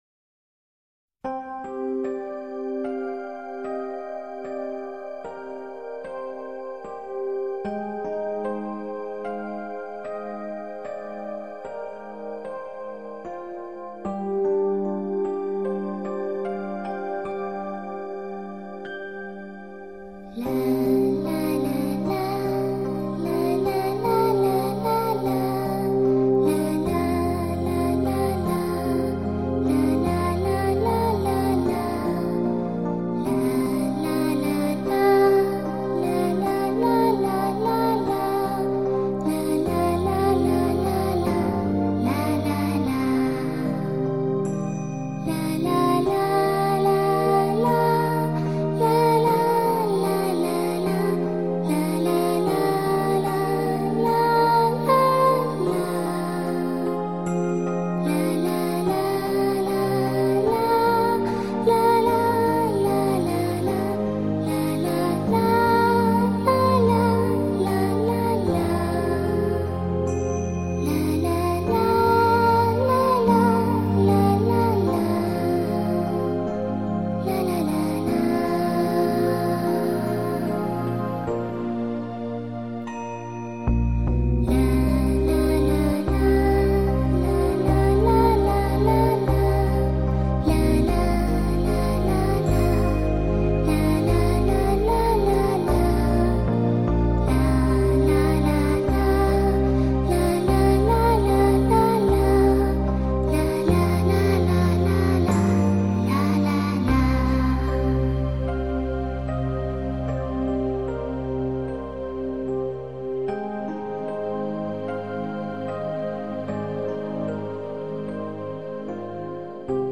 听上去怎么感觉这么伤感呢。 。